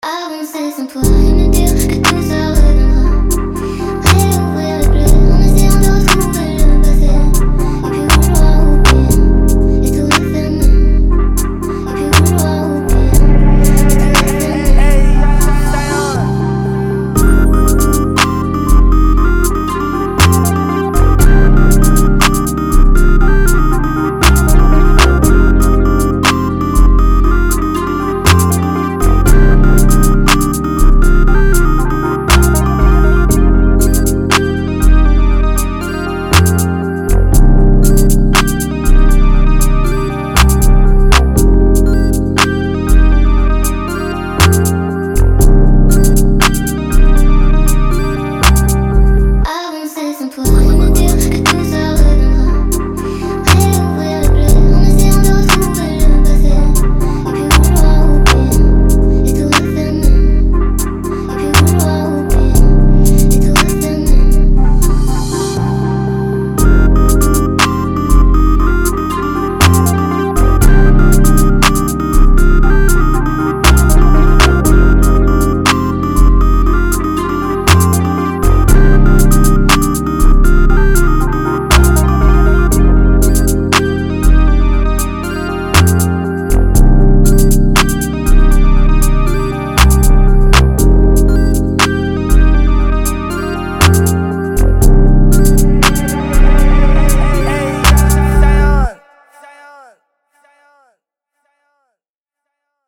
119 C Minor